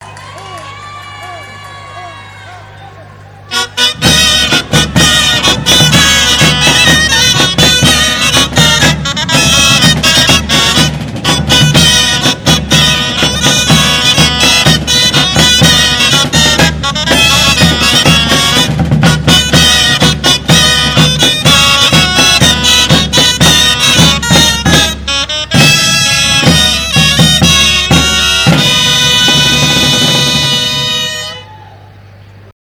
BALL DE GITANES DE TARRAGONA MARXA Grallers Tocaferro